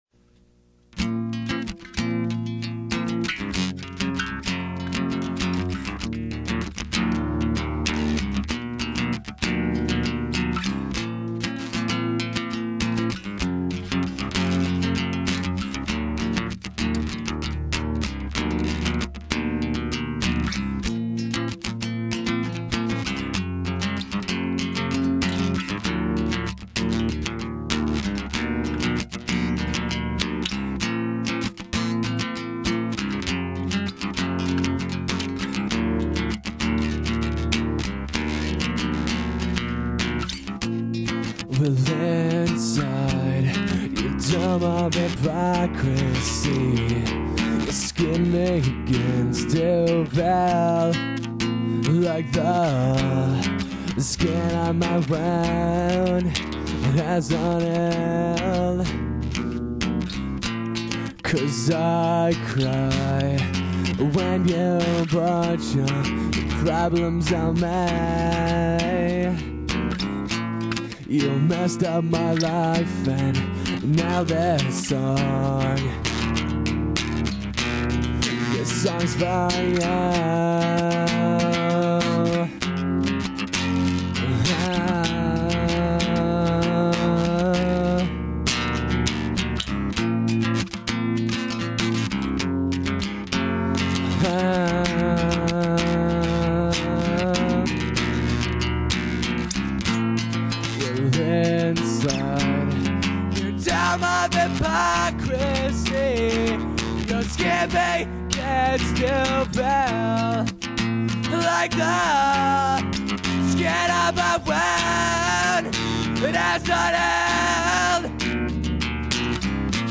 acoustic style
Acoustic